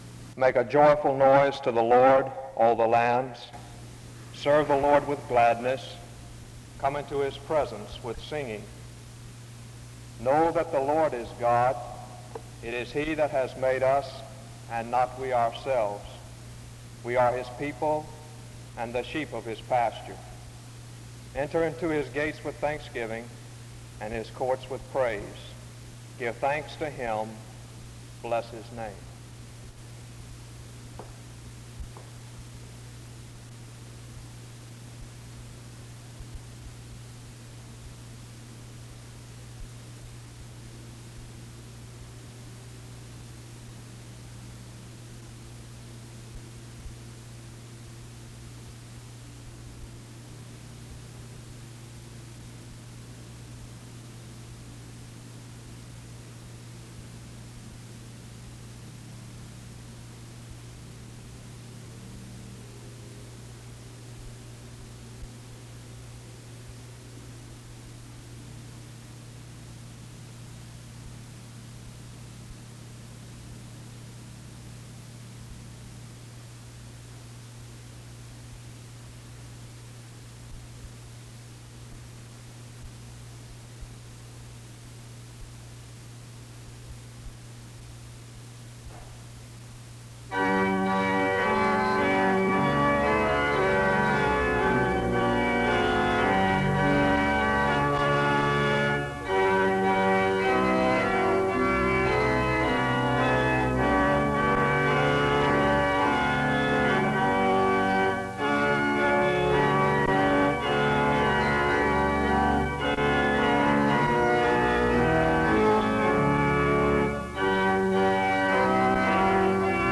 The service begins with a scripture and music from 0:00-2:09. There is a prayer from 2:32-4:01. An introduction to the speaker is given from 4:10-5:25.
A closing prayer is offered from 27:55-28:12.